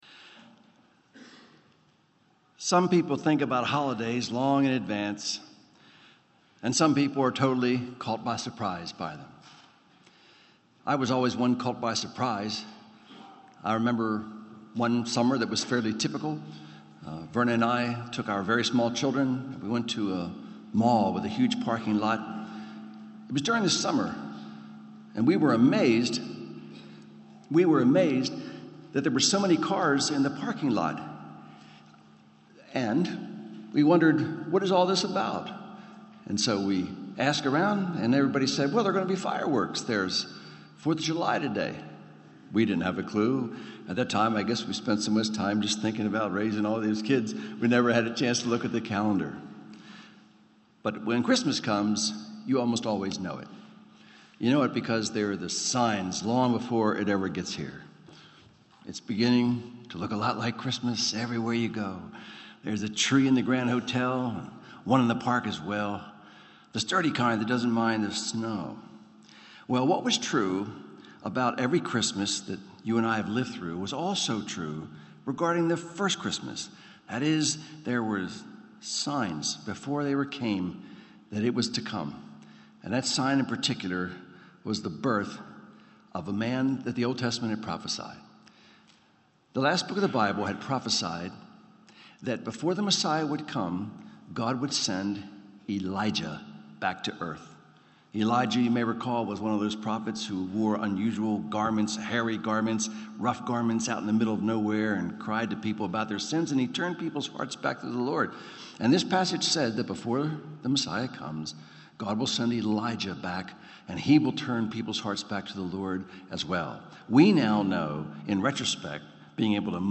Sermons on Luke 1:39-45 — Audio Sermons — Brick Lane Community Church